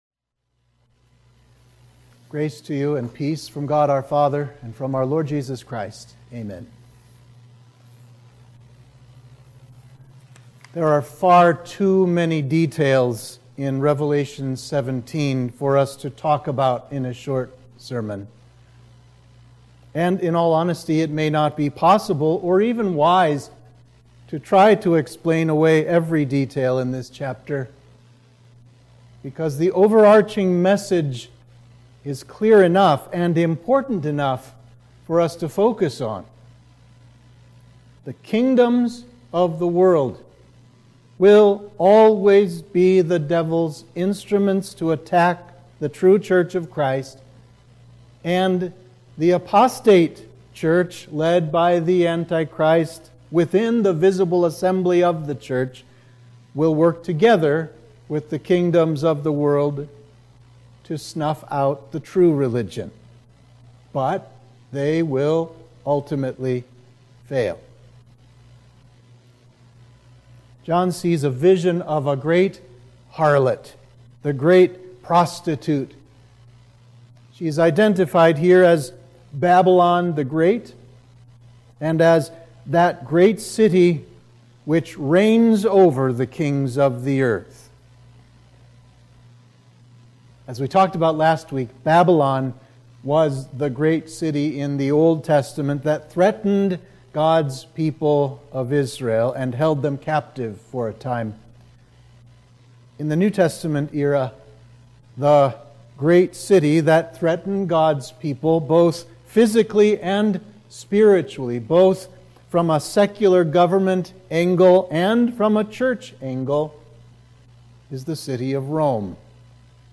Sermon for Midweek of Trinity 13